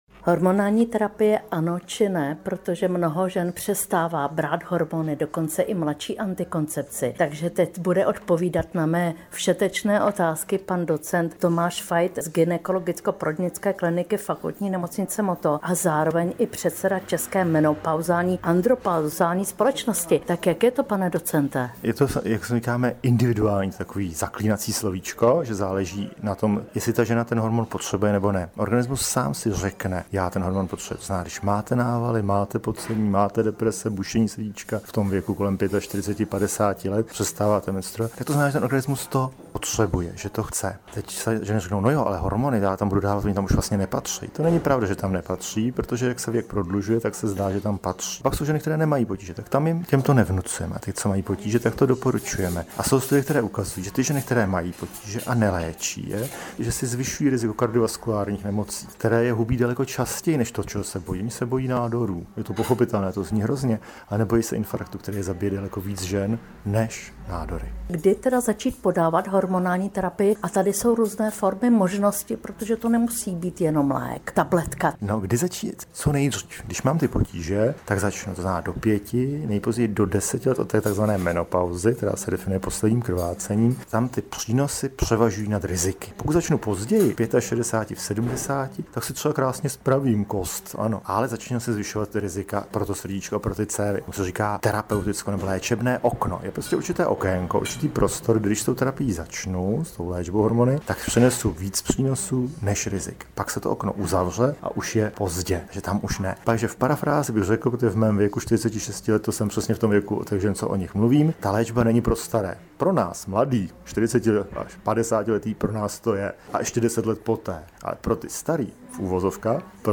Rozhovor o hormonální terapii